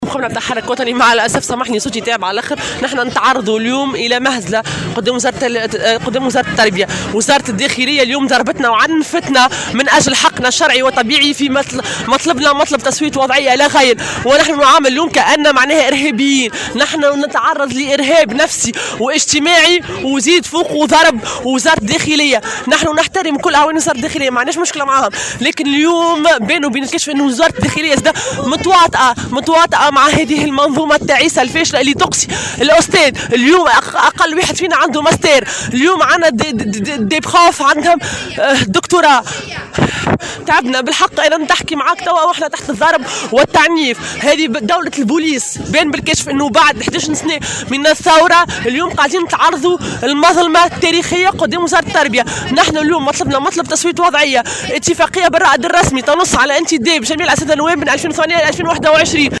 في تصريح لمراسل "الجوهرة أف أم"